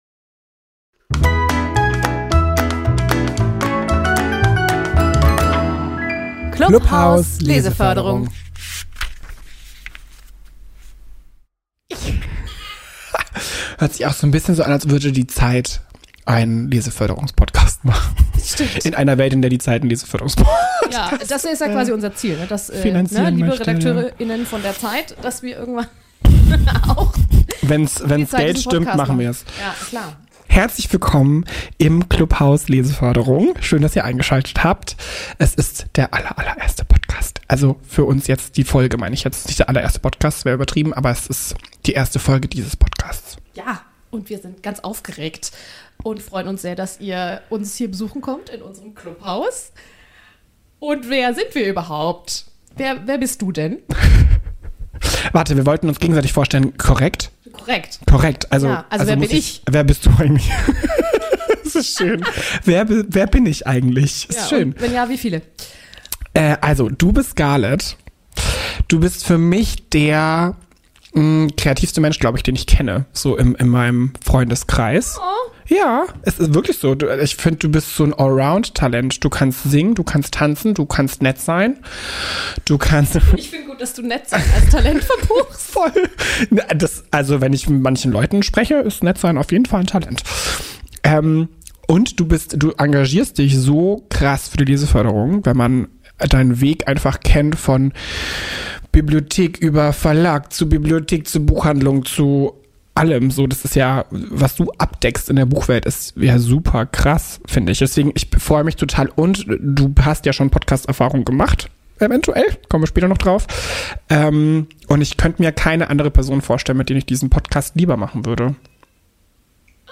Dieses Problem haben wir schon für die nächste Folge behoben.